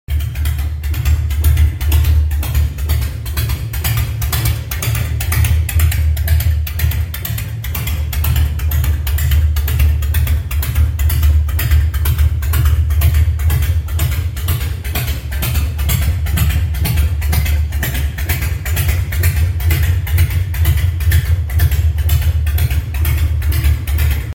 after all the time Mp3 Sound Effect Oof, after all the time she sat, still runs beautifully.